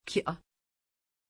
Pronunciation of Kiah
pronunciation-kiah-tr.mp3